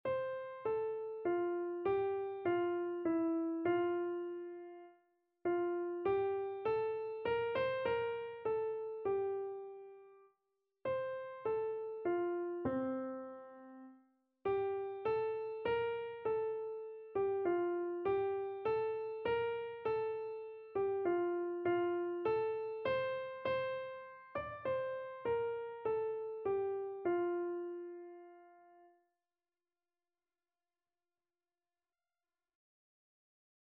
Free Sheet music for Keyboard (Melody and Chords)
3/4 (View more 3/4 Music)
F major (Sounding Pitch) (View more F major Music for Keyboard )
Keyboard  (View more Easy Keyboard Music)
Classical (View more Classical Keyboard Music)